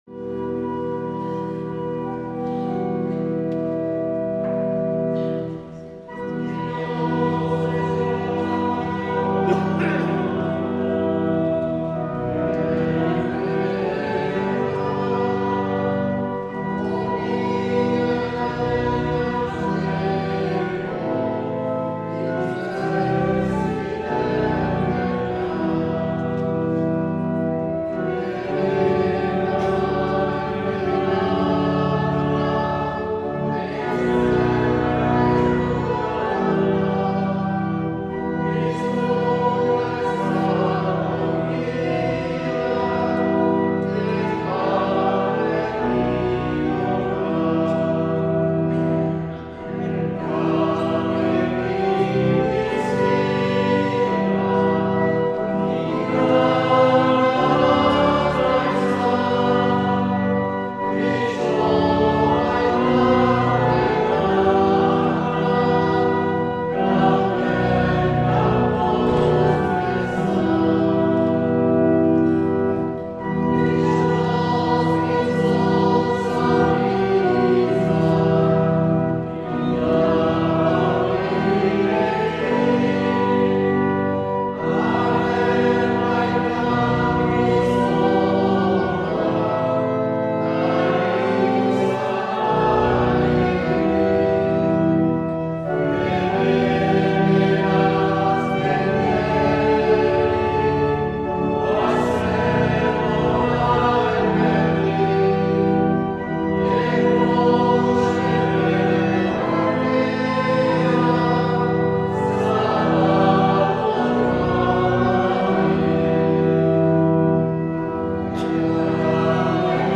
2023-01-22 Urteko 3. Igandea A - Urruña